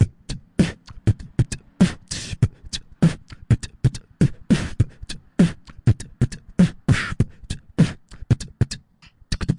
描述：一个可循环的机器人幽默片。搞笑。